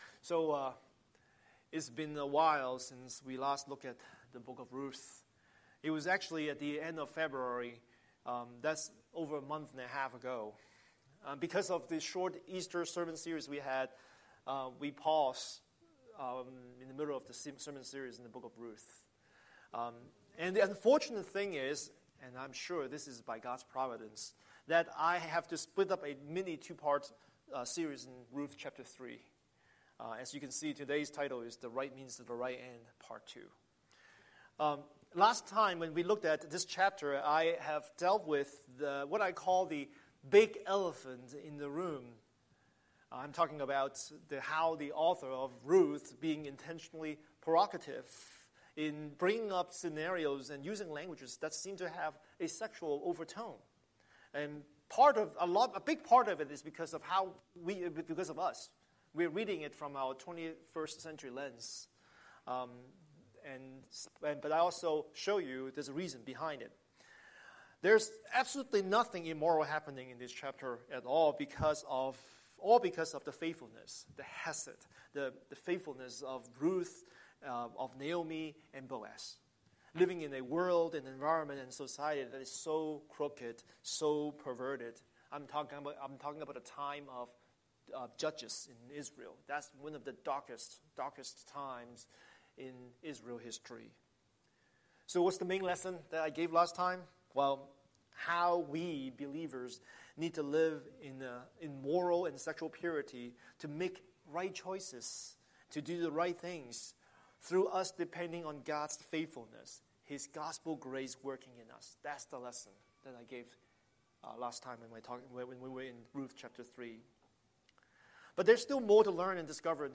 Scripture: Ruth 3:1-18 Series: Sunday Sermon